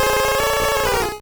Cri de Feunard dans Pokémon Rouge et Bleu.